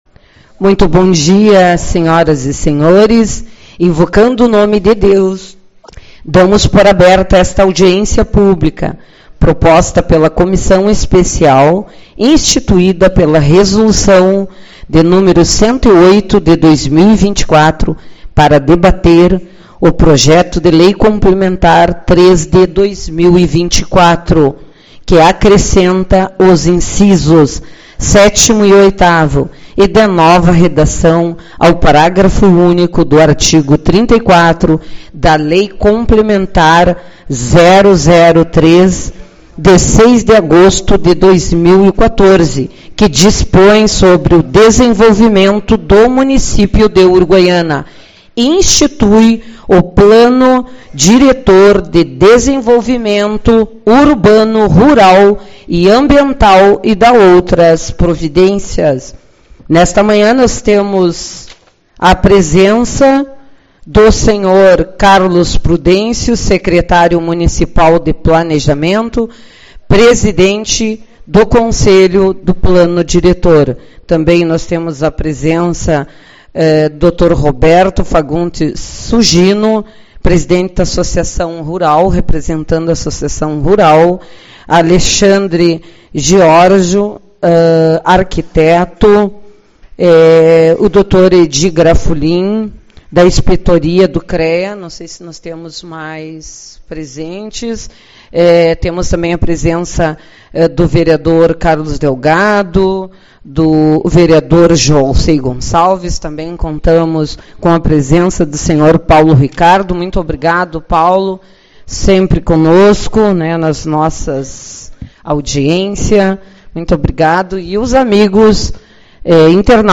23/10 - Audiência Pública-Alteração no Plano Diretor